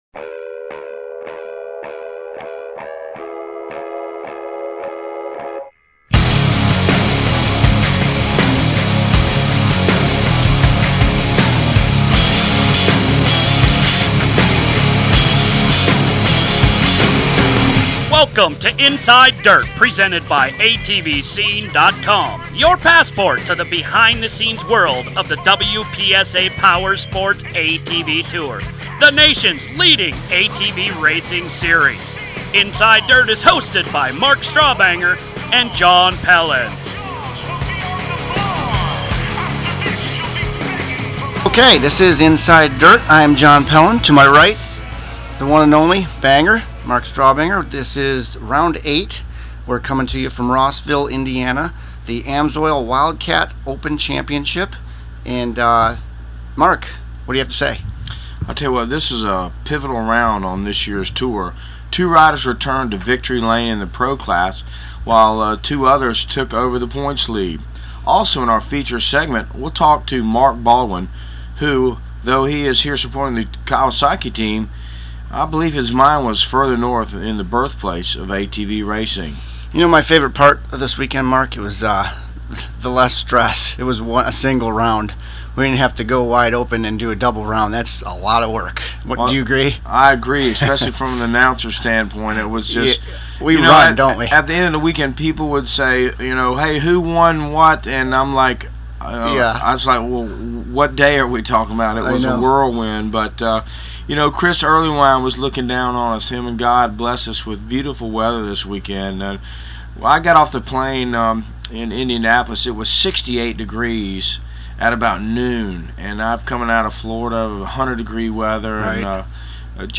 Recap of Rossville, IN- interviews